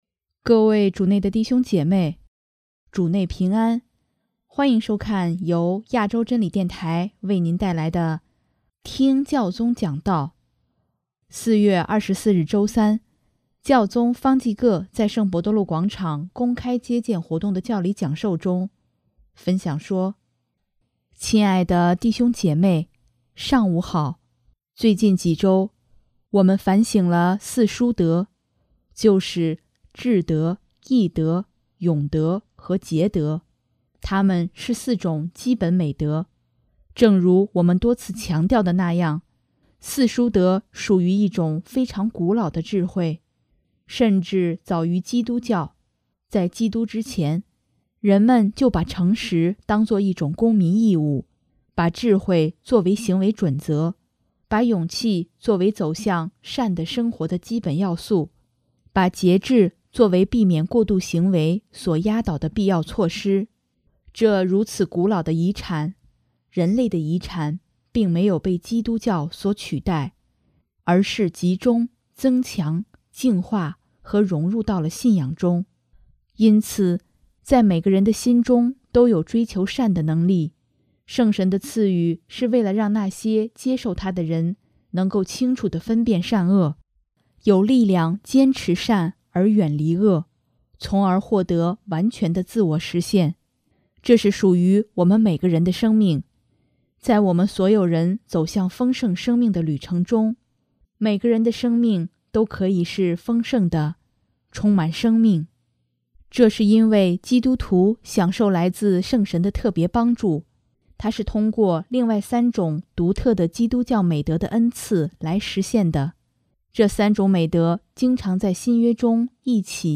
4月24日周三，教宗方济各在圣伯多禄广场公开接见活动的教理讲授中，分享说：